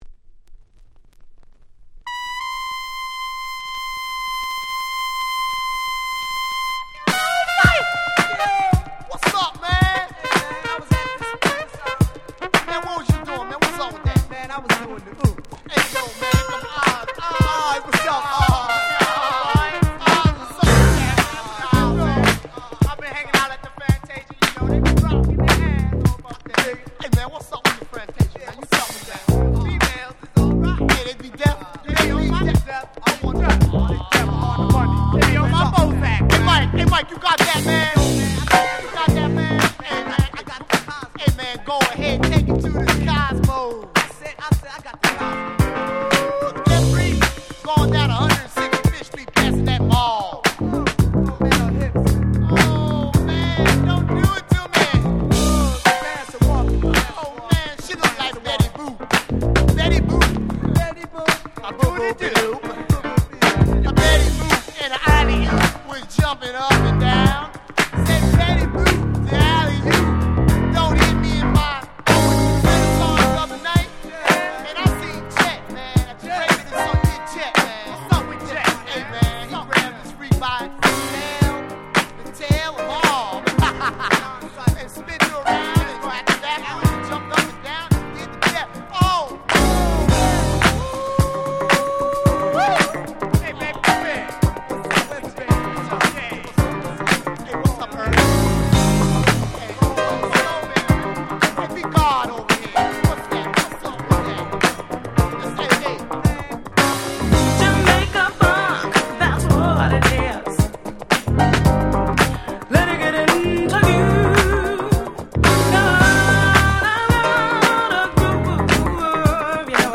80' Super Hit Disco / Dance Classics !!